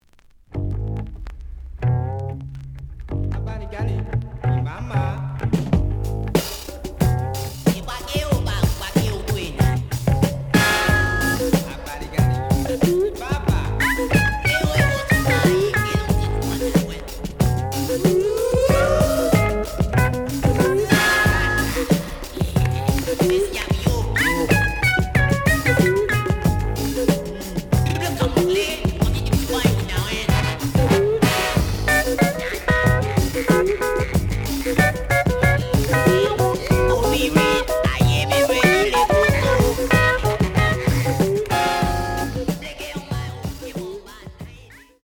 The audio sample is recorded from the actual item.
●Genre: Jazz Funk / Soul Jazz